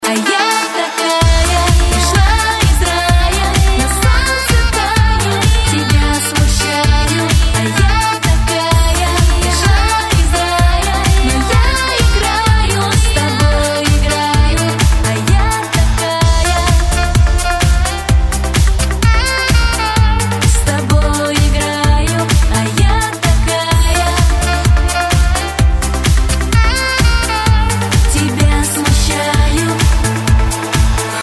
Рингтоны русские